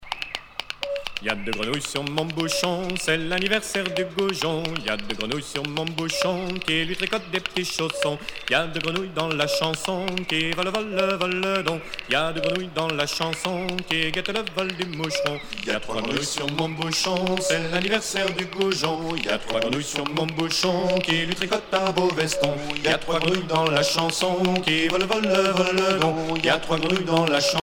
danse : branle : courante, maraîchine ;
Pièce musicale éditée